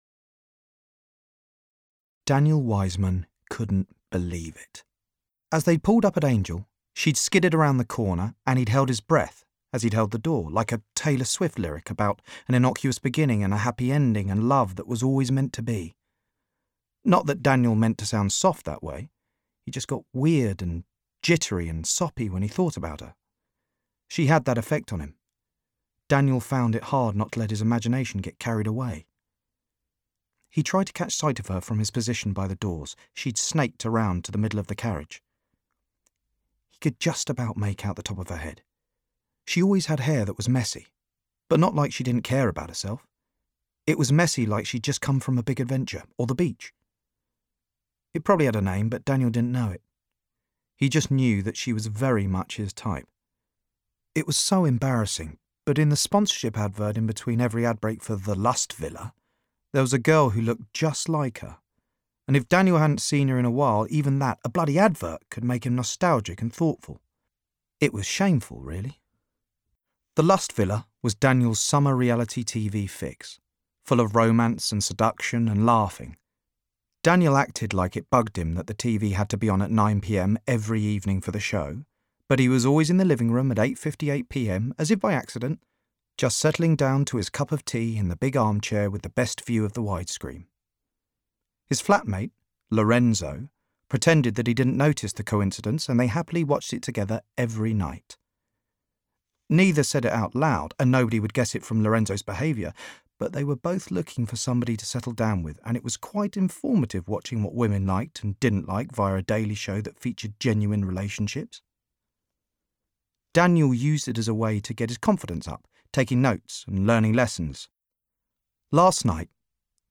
Voice Reel
Audiobook - Fiction